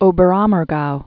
(ōbər-ämər-gou)